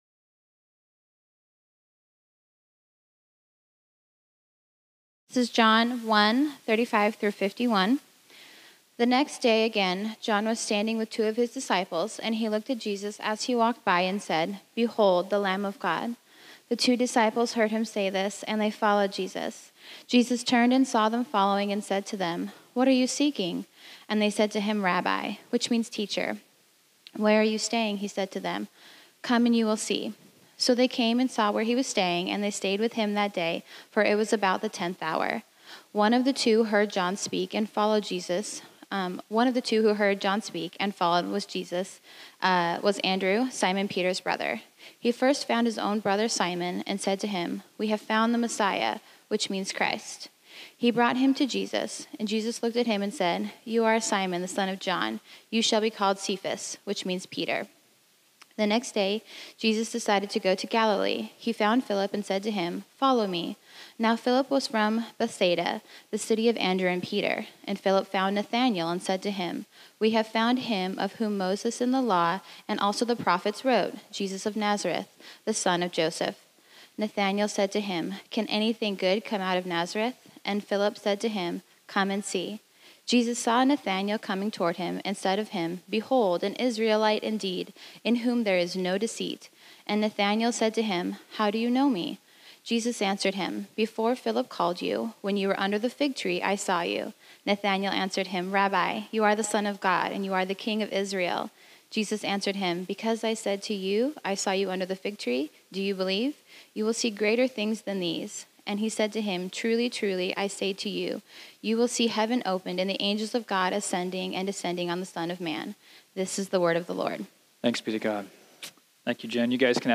This sermon was originally preached on Sunday, September 22, 2019.